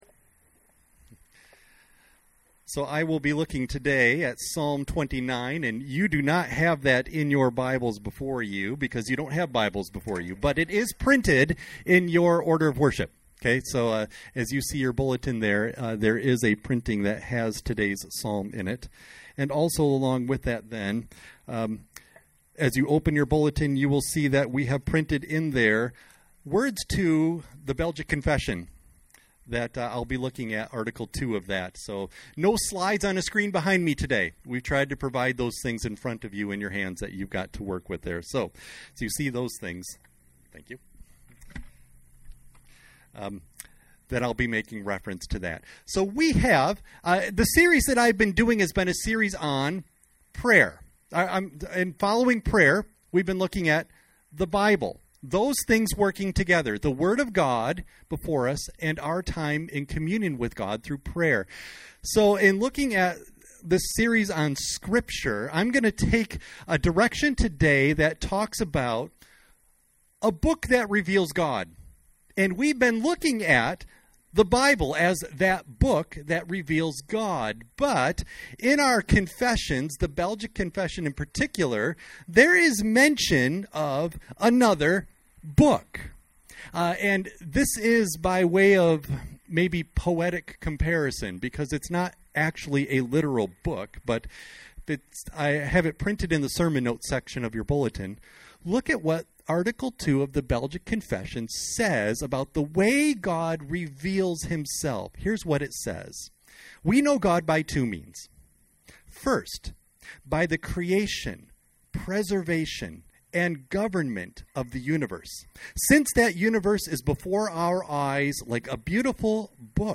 Audio of Message